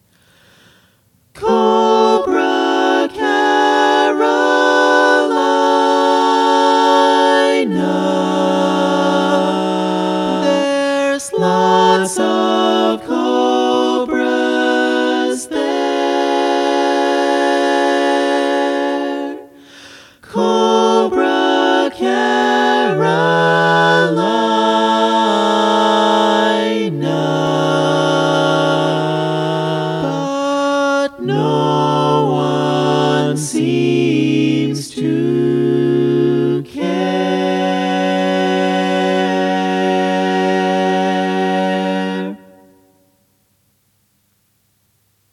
Key written in: F# Minor
How many parts: 4
Type: Barbershop
All Parts mix: